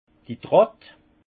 Haut Rhin d' Trott
Ville Prononciation 68 Munster